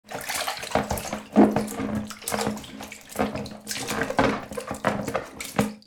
Download Free Foley Sound Effects | Gfx Sounds
Small-bath-air-toy-soft-water-splashes.mp3